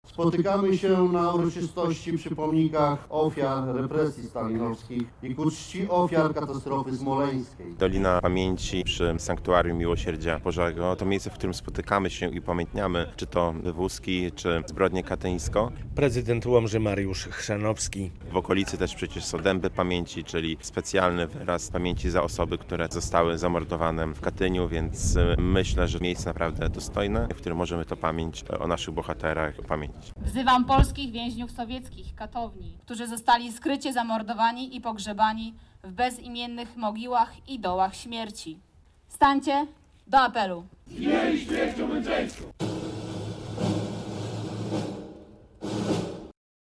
Obchody rocznicy katastrofy smoleńskiej, Zbrodni Katyńskiej i masowej deportacji na Sybir w Łomży - relacja